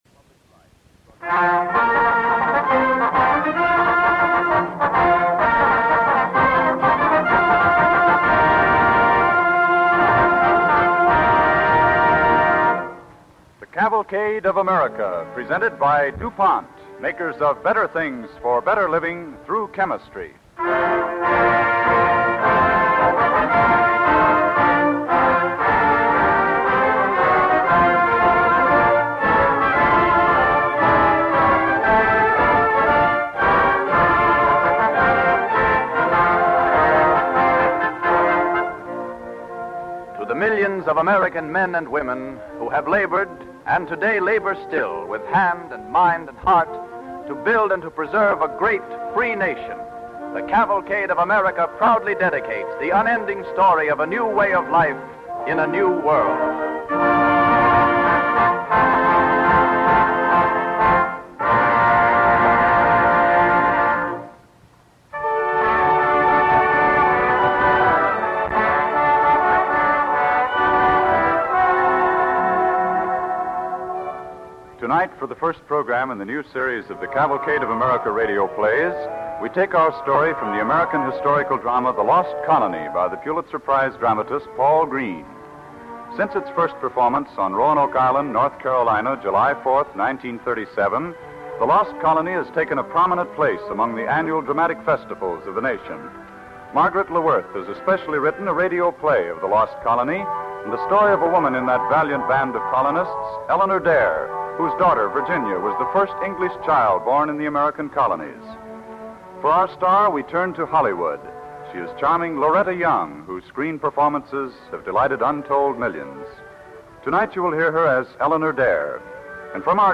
The Lost Colony, starring Loretta Young